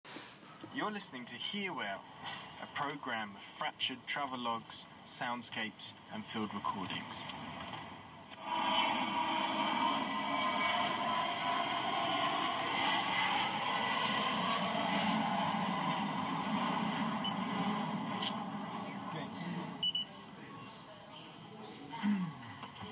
FM Mitschnitt